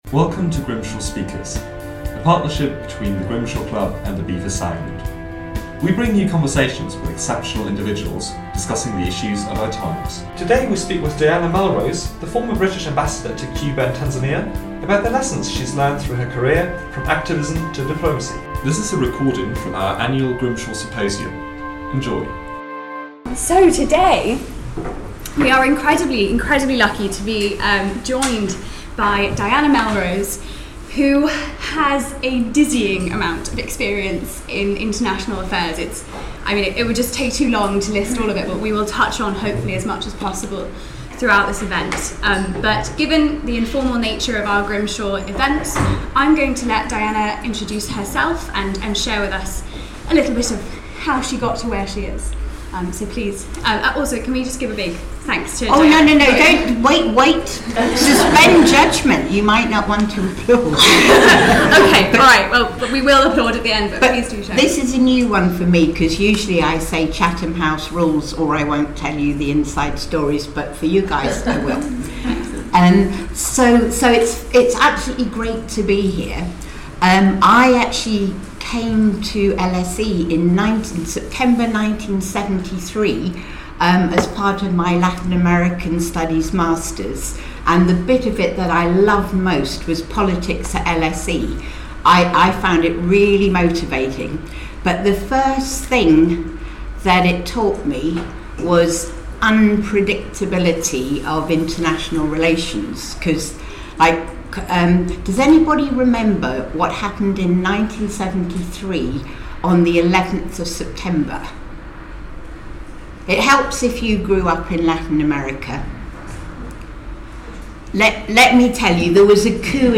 At our recent Grimshaw Symposium on The Changing Face of International Affairs, we spoke to Dianna Melrose, former British Ambassador to Cuba and Tanzania.
We bring you conversations with exceptional individuals, discussing the issues of our times.